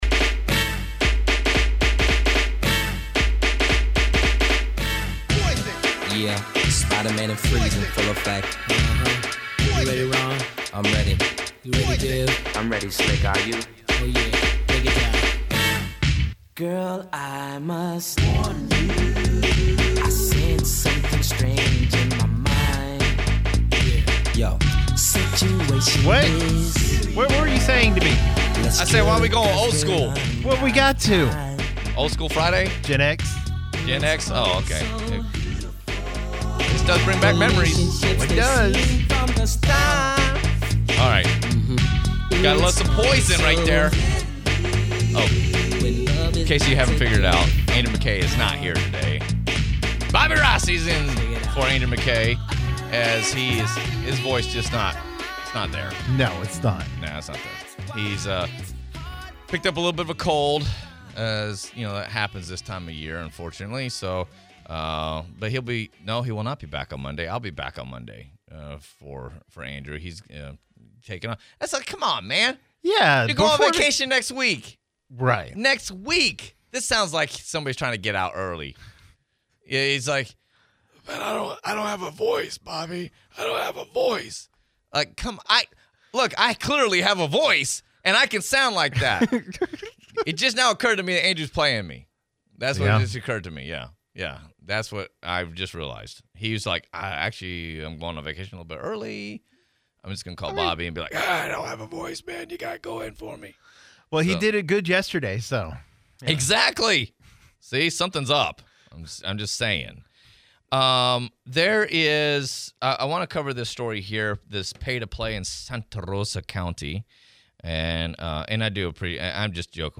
OLF-8, Lakeview Interview